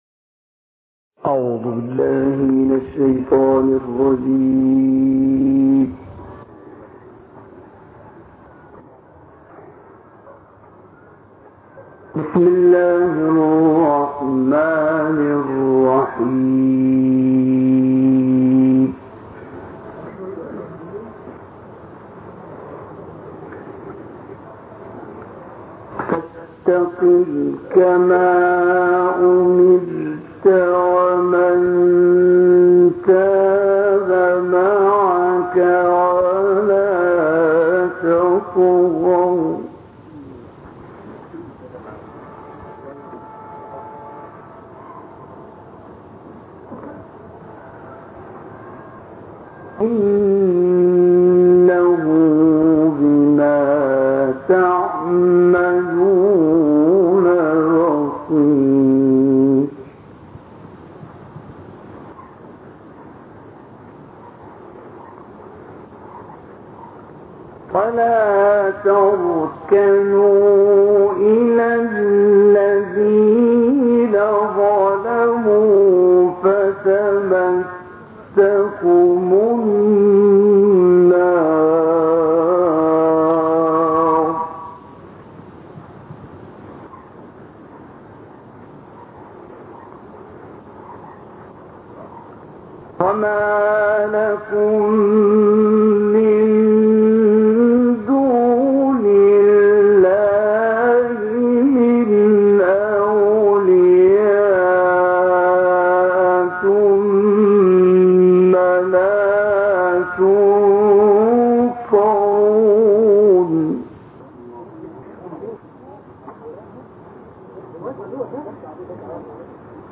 تلاوت سوره‌های
تلاوت سوره‌های "هود" و "یوسف" را با صدای استاد حمدی محمود الزامل می‌شنوید.